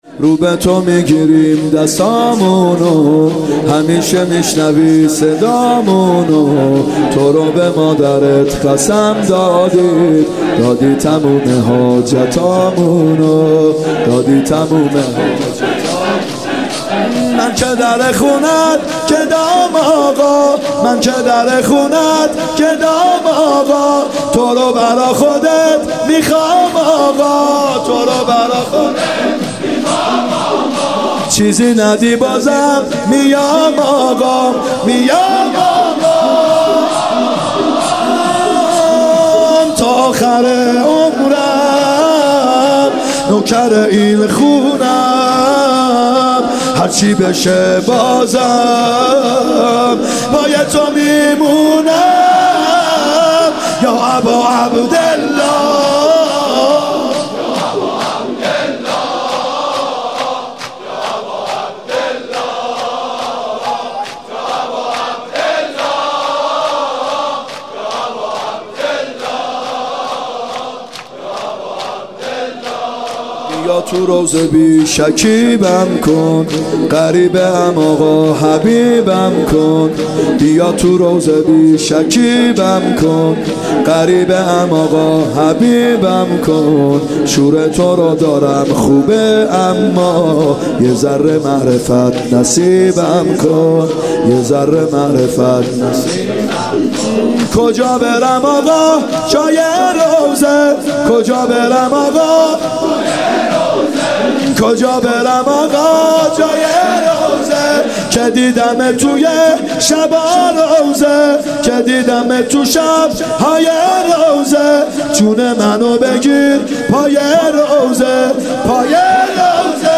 محرم 94(هیات یا مهدی عج)